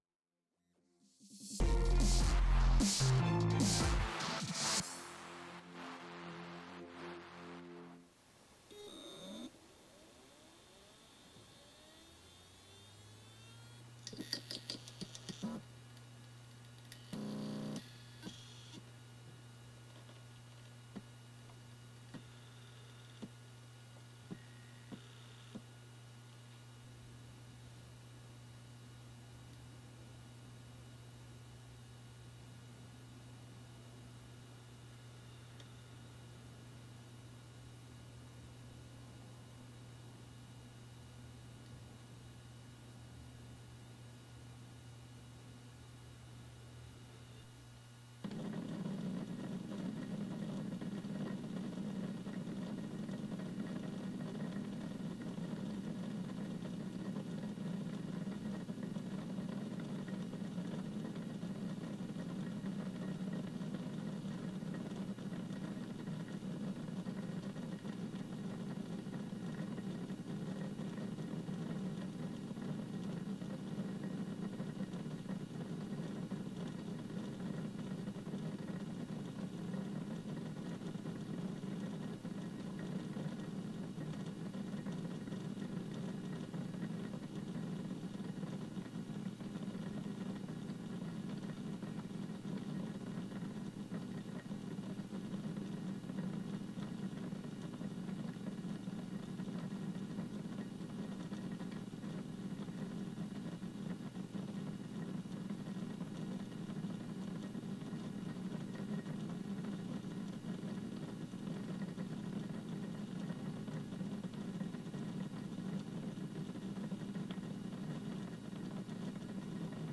HDD noise levels table/list